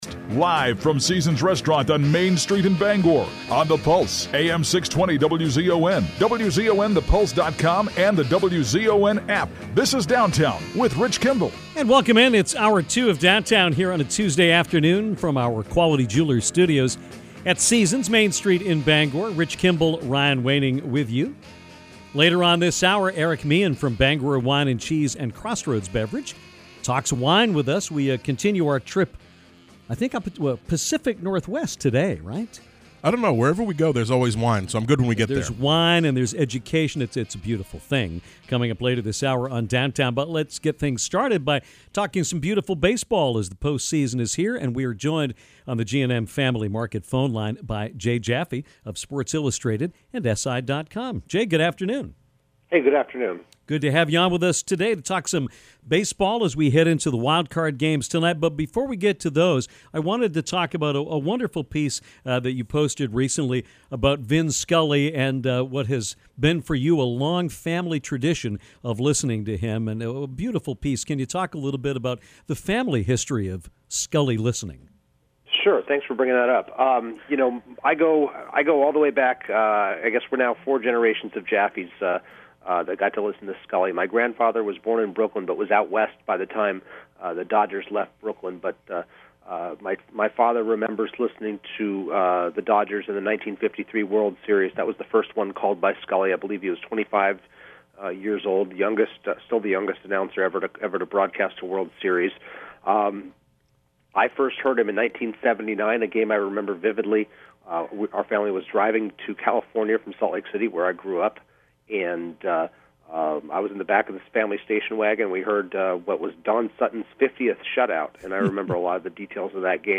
Get inside stuff in this interview.